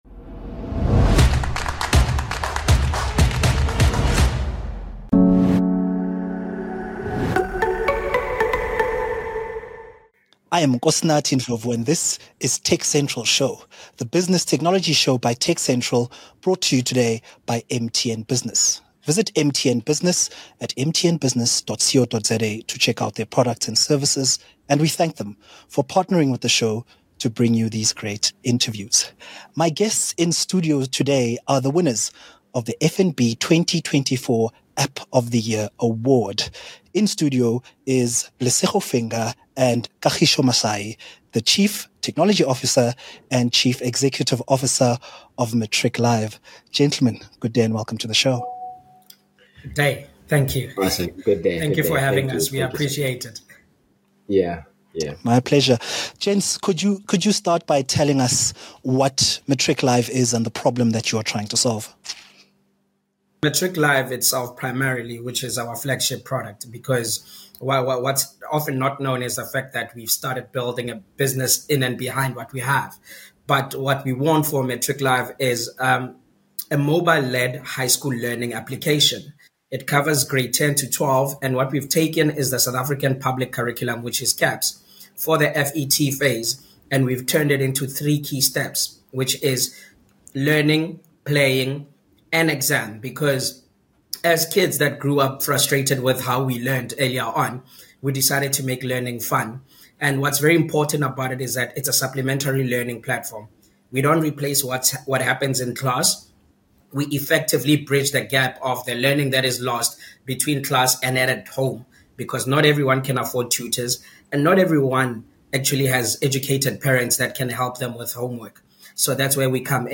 The TechCentral Show (TCS, for short) is a tech show produced by South Africa's leading technology news platform. It features interviews with newsmakers, ICT industry leaders and other interesting people.